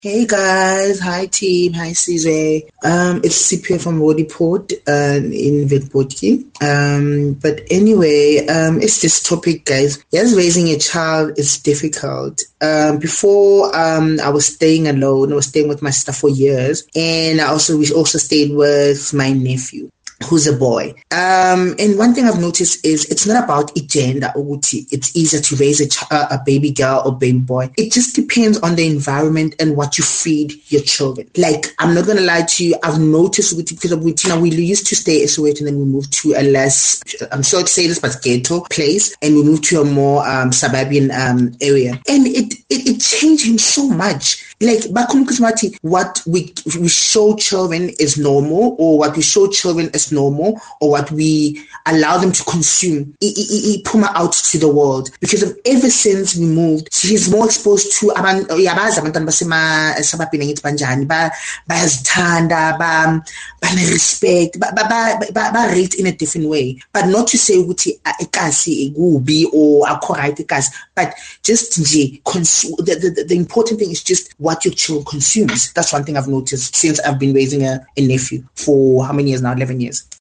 Kaya Drive listeners shared their experience: